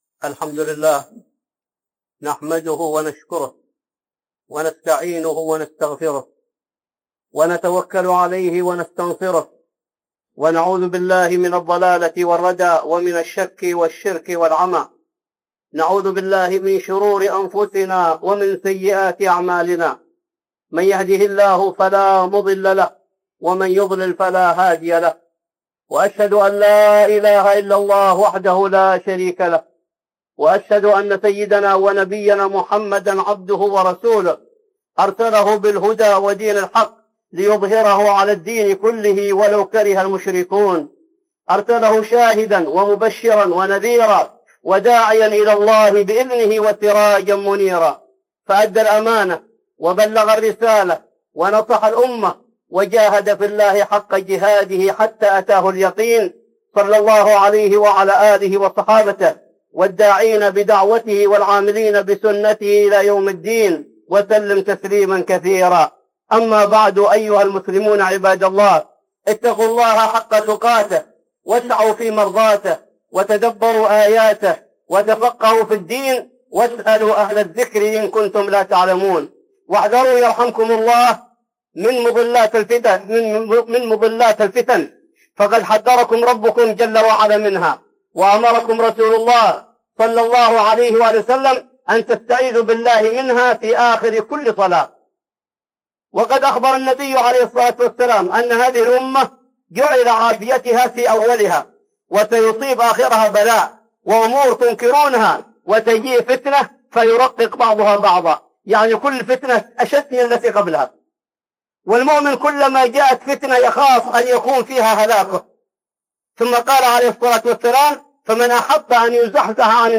(خطبة جمعة) التعامل مع الفتن